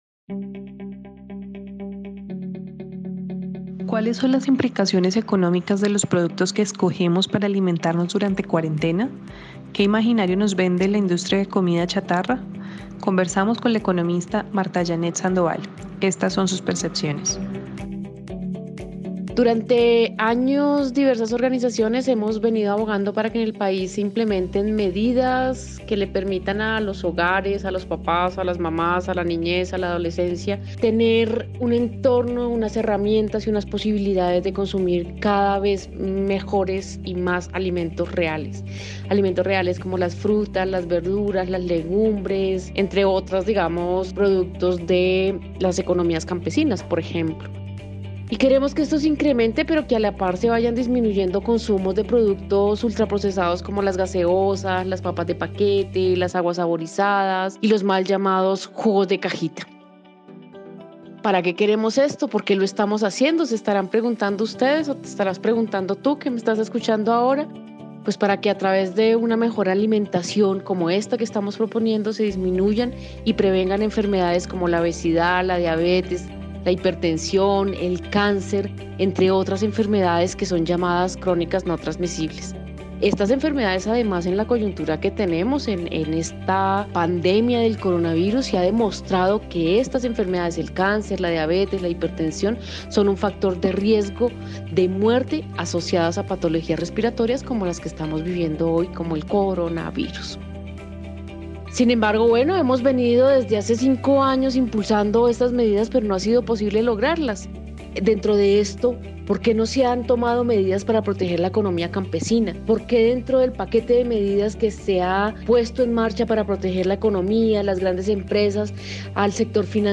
Escucha una serie de audios sobre el derecho a la alimentación, bajo la voz de diferentes expertas.